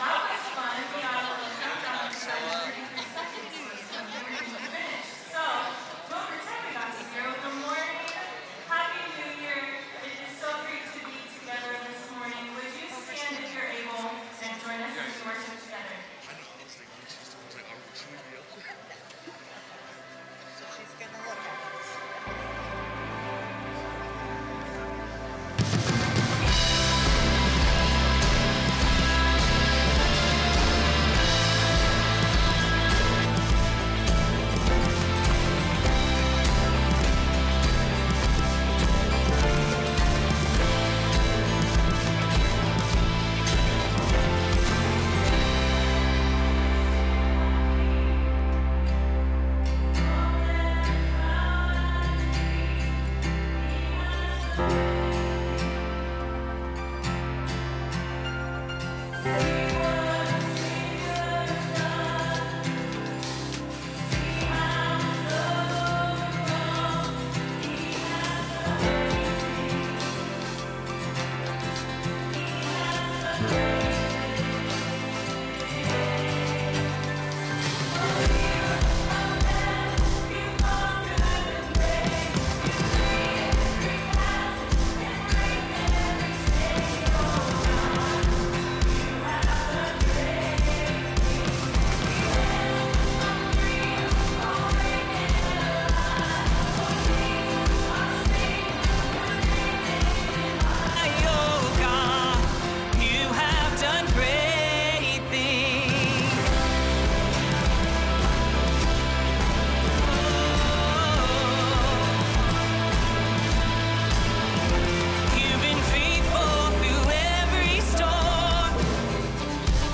Sermons - N E W L I F E